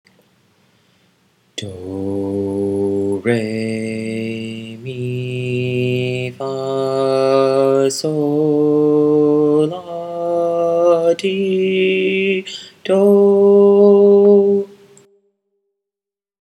These syllables line up with the notes of a major scale.
major.m4a